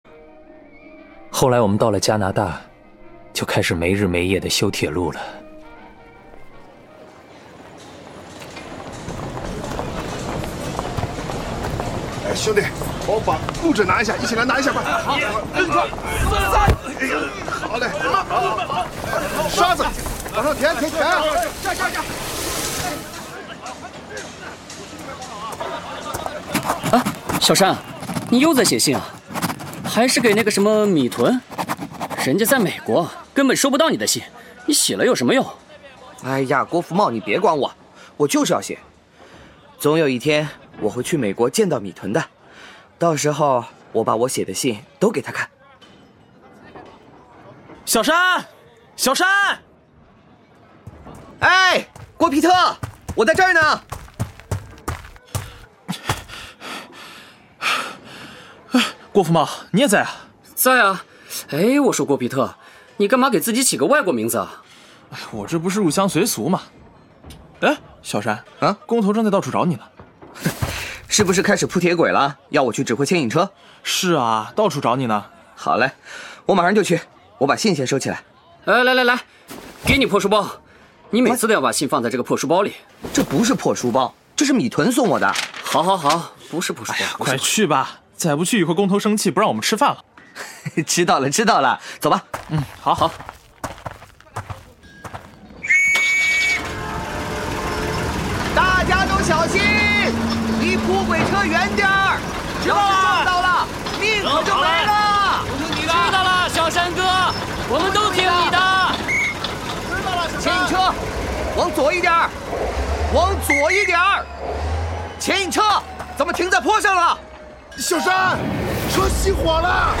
• 广播类型：现代剧
这是一篇反映民族自尊的广播剧。